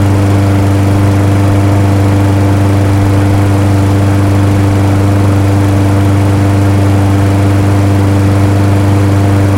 割草机 " CM割草机空转2
描述：割草机空转的声音。
Tag: 切割机 发动机 空闲 美化 马达 草坪 牧草 户外 割草机